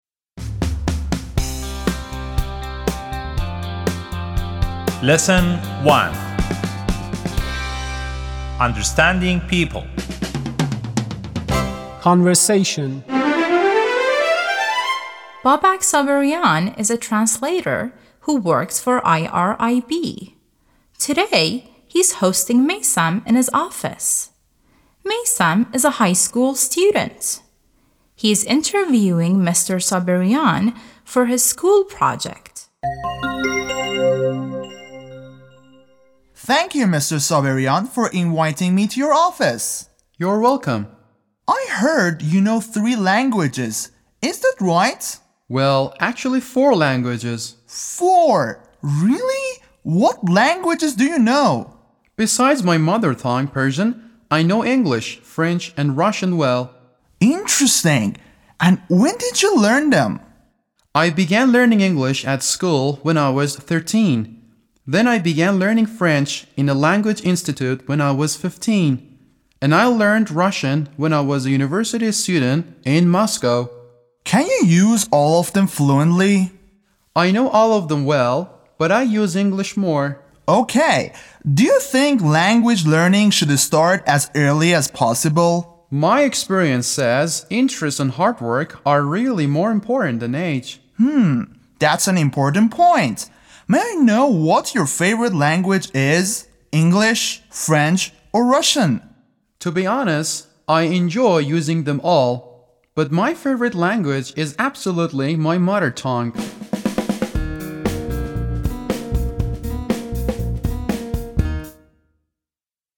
11-L1-Conversation.mp3